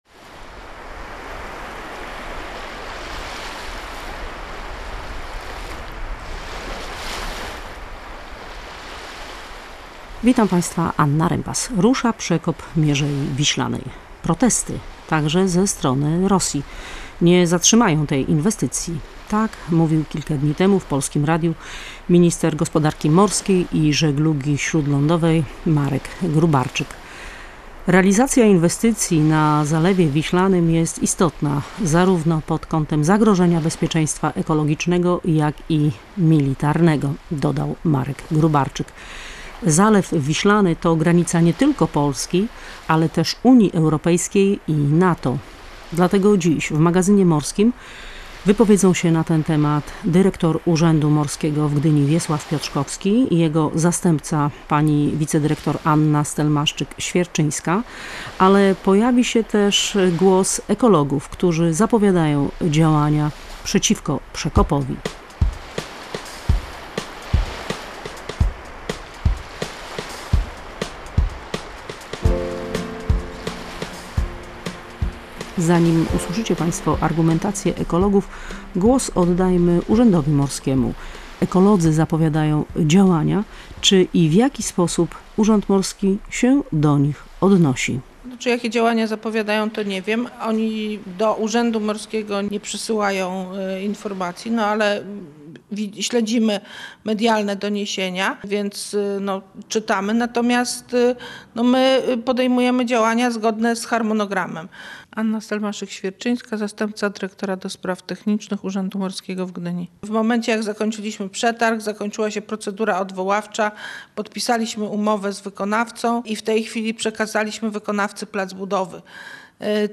Nie wszystkim podoba się ten pomysł i w magazynie morskim wyjaśniamy dlaczego. Prezentujemy stanowiska za i przeciw.